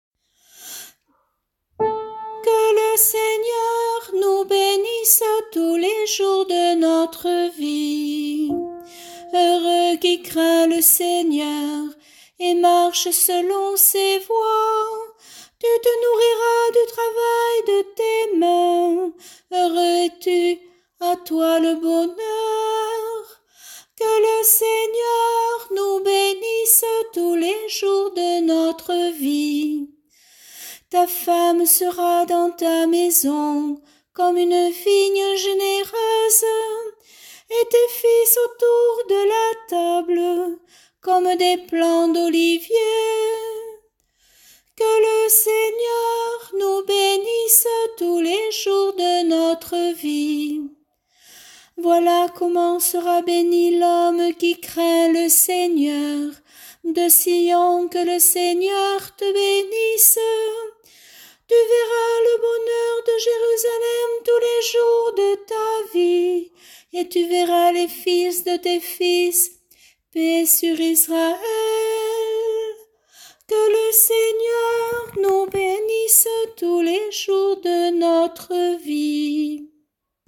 Chorale psaumes année B – Paroisse Aucamville Saint-Loup-Cammas